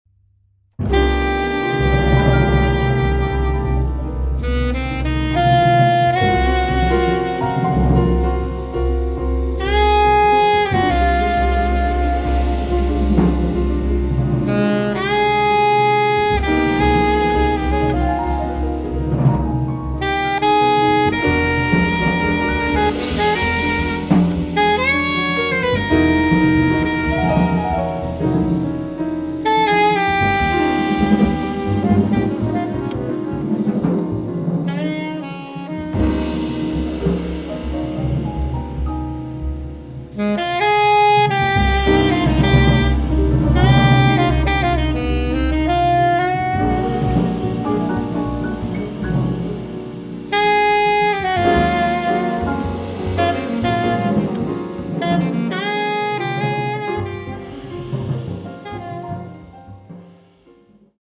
sax
piano
bass
drums